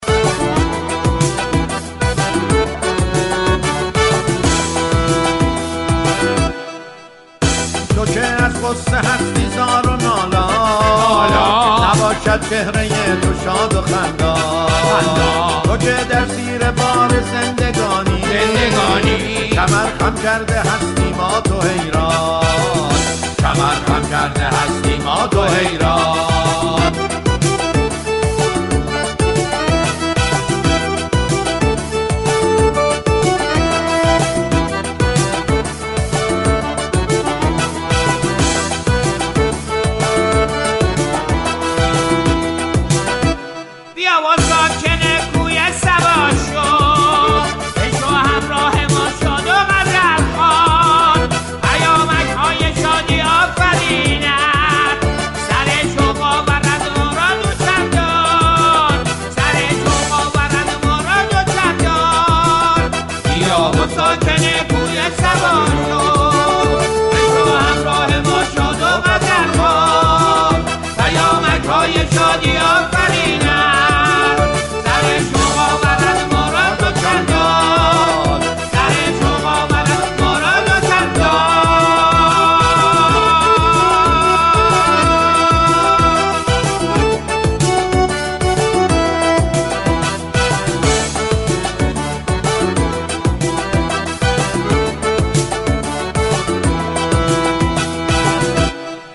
این برنامه هر روز در فضایی شاد با پخش موسیقی ، ترانه و اجرای نمایش های طنز به بازیگری پیشكسوتان رادیو لحظات شادی را تقدیم مخاطبان می كند و هر روز یكی از مسائل ساده و روزمره زندگی را سوژه طنز قرار می دهد ، «صبامك» تلاش می كند با تاكید بر اخلاق مداری در كوچكتری مسائل زندگی و توجه بیشتر به خانه و خانواده به اهمیت این موضوع بپردازد.
این برنامه روز سه شنبه نهم آبان با موضوع فقل های زندگی راهی آنتن می شود، در این برنامه عمو صبامكی پیامك های مخاطبان را كه مربوط به موضوع برنامه هستند را در قالب اجرای نمایش می خواند و به آنها پاسخ می دهد.